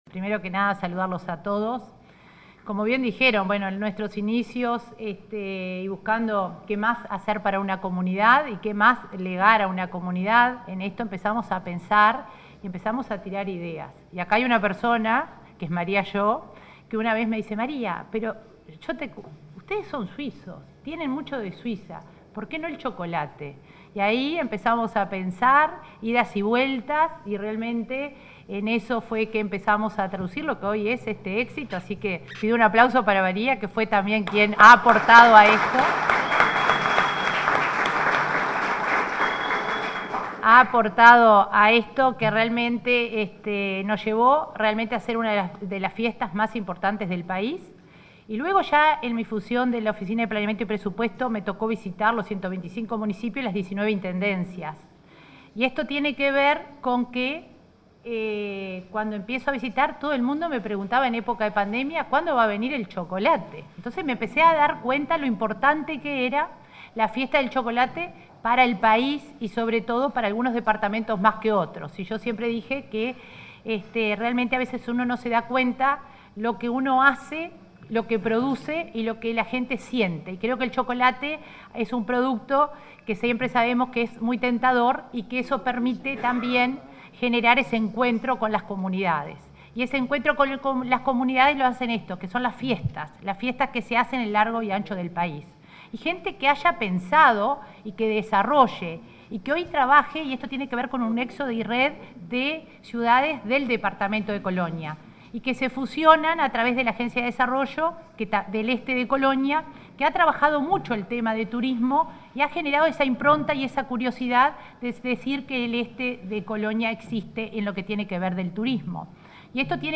Palabras de la coordinadora de Descentralización de OPP, María de Lima
Palabras de la coordinadora de Descentralización de OPP, María de Lima 13/07/2023 Compartir Facebook X Copiar enlace WhatsApp LinkedIn La coordinadora de Descentralización de la Oficina de Planeamiento y Presupuesto (OPP), María de Lima, participó en el lanzamiento de la Fiesta Nacional del Chocolate, que se desarrollará el 23 de este mes en Nueva Helvecia, departamento de Colonia.